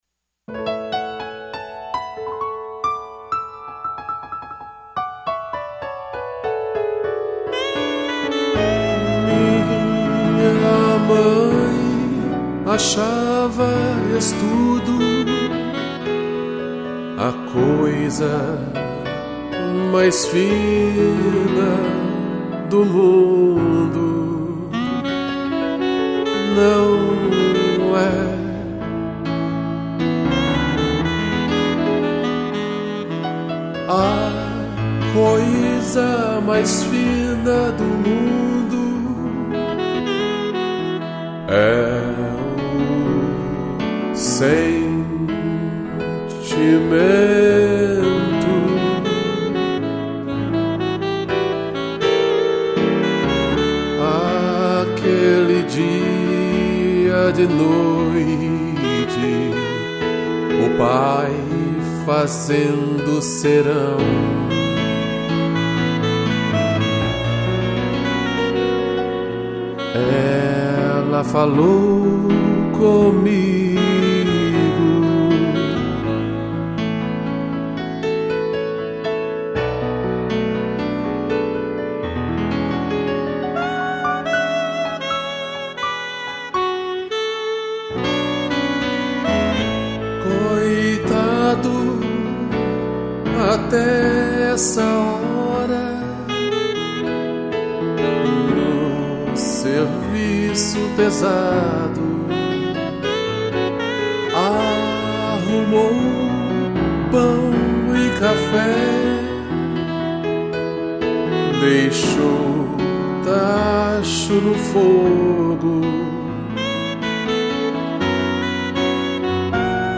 voz
piano e sax